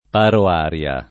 [ paro # r L a ]